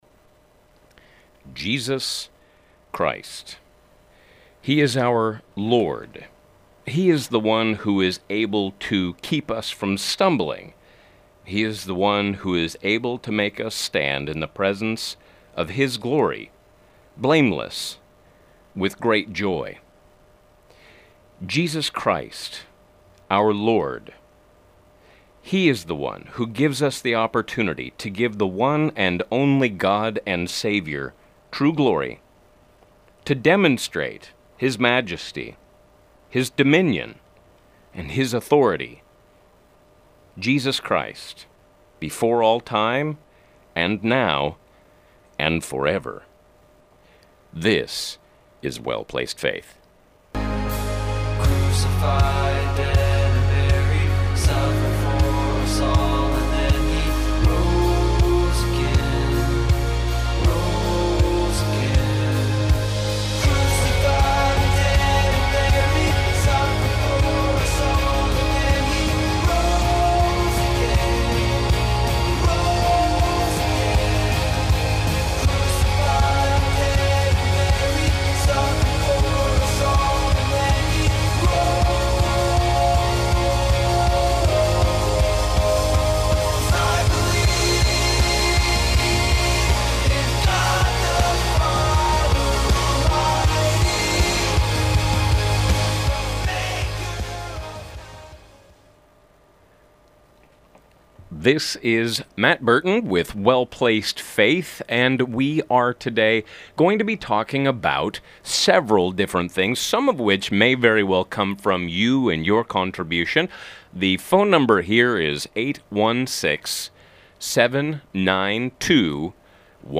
Christians (follower of Christ, the Church) are at a controlled disequilibrium with the world A caller compares the stones mentioned in Acts 7:51-60 to the media and ballot box of today.